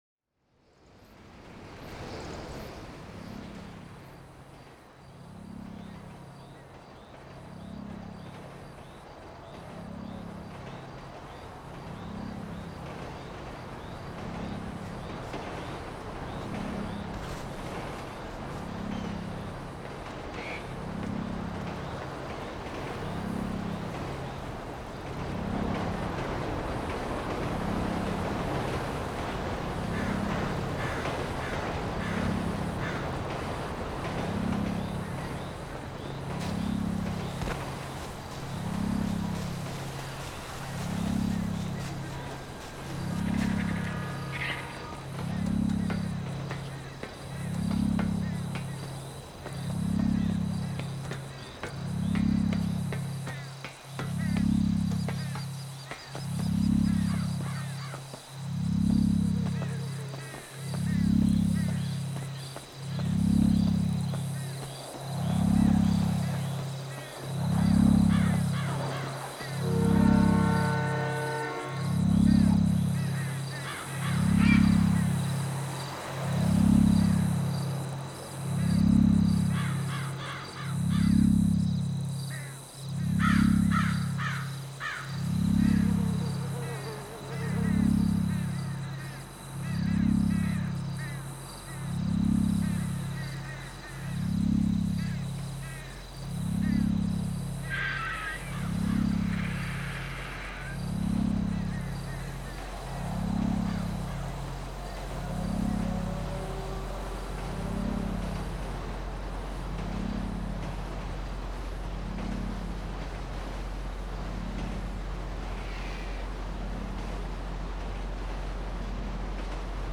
All recordings taken from journeys through open world gameplay, directly onto a Zoom H6 Recorder, with minor editing and equalisation in Protools.
2 Peaks, flies, birds, radioactivity, trains and subterranean areas
peaks_flies_birds_radioactivity_trains_and_subterranean_areas__excerpt.mp3